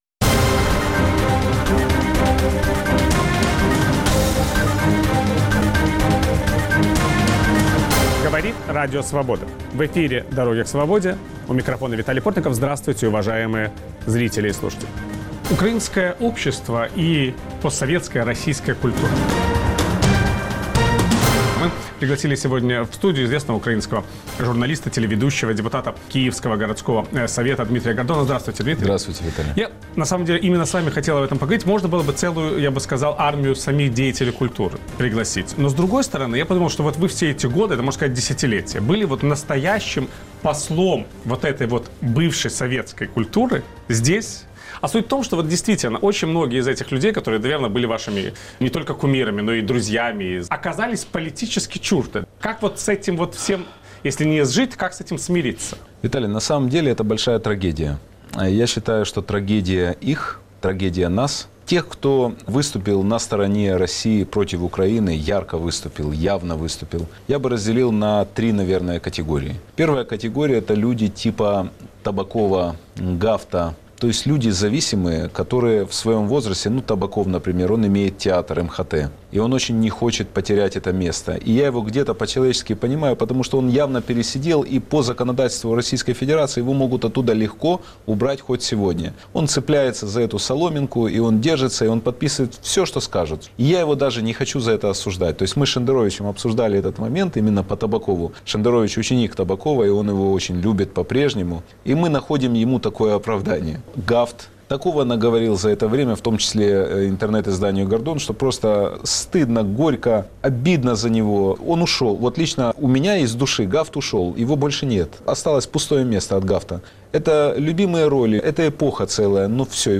Как меняется отношение украинцев к некогда культовым персонажам из советского прошлого - актерам, писателям, эстрадным исполнителям? Ведущий программы "Дороги к свободе" Виталий Портников беседует с украинским журналистом, телеведущим, депутатом Киевского горсовета Дмитрием Гордоном.